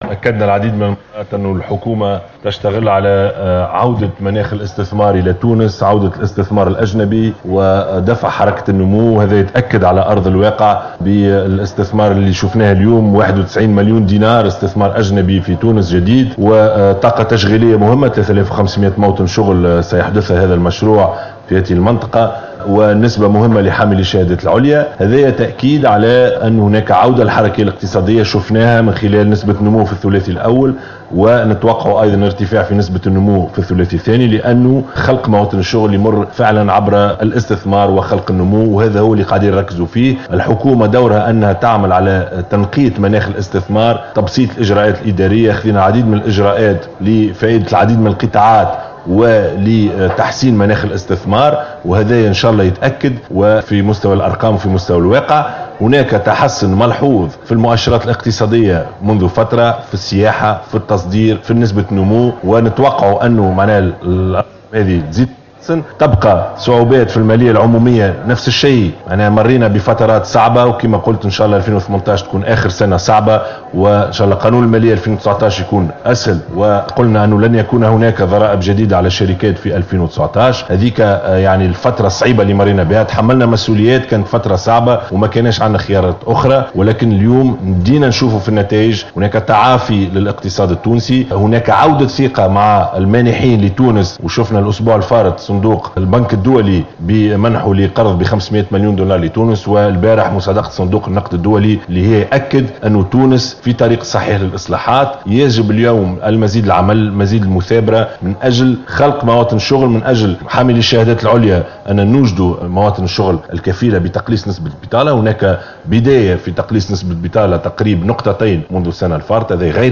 قال ر ئيس الحكومة يوسف الشاهد، خلال إشرافه على وضع حجر الأساس للمصنع المزمع احداثه من قبل شركة "PSZ TUNISIE" بالمنطقة الصناعية ببوحجر من ولاية المنستير، إن اختيار هذه الشركة الألمانية تونس لفرعها الرابع في العالم، دليل ثابت على تعافي الاقتصاد التونسي وتحسن النمو.